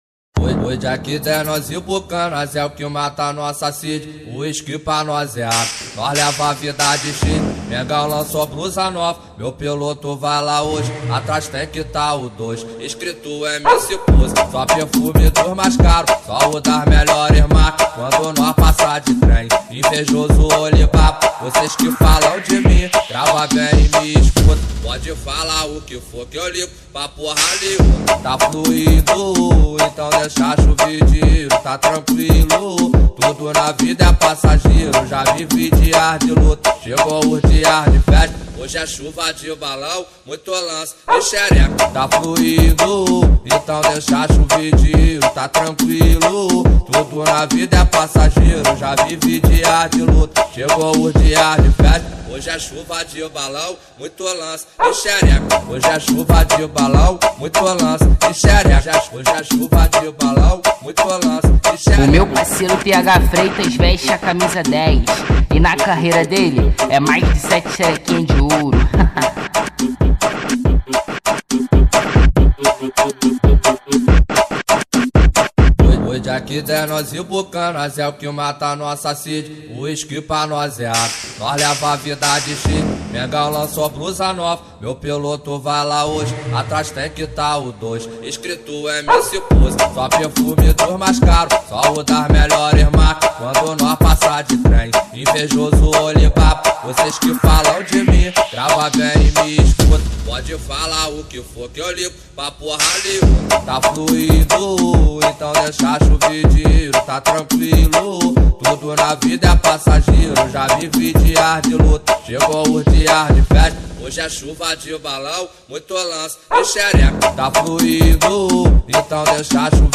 2024-02-20 11:19:58 Gênero: Trap Views